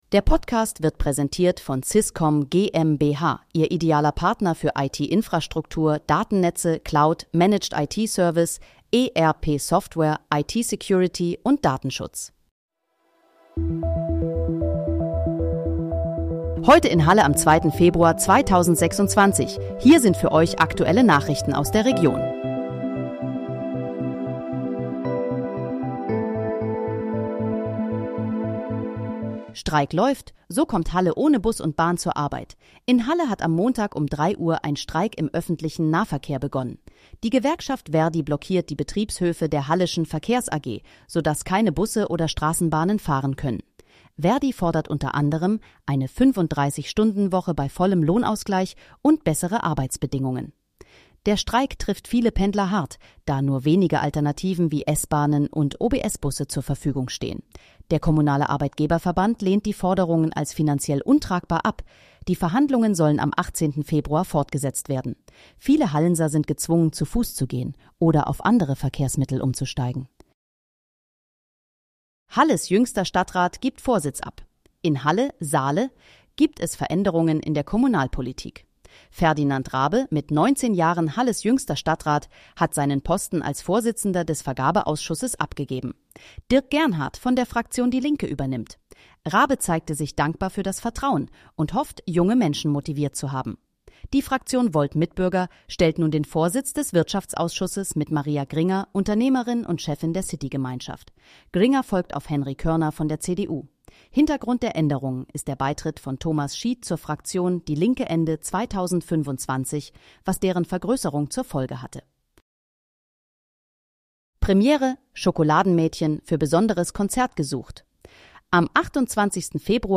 Heute in, Halle: Aktuelle Nachrichten vom 02.02.2026, erstellt mit KI-Unterstützung
Nachrichten